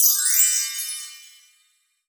chimes_magical_bells_01.wav